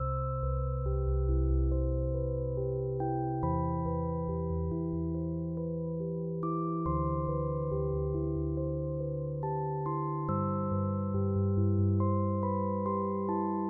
Chill Rhodes
描述：hill rhodes chords and simple melody on top
标签： 140 bpm Chill Out Loops Piano Loops 2.31 MB wav Key : Unknown
声道立体声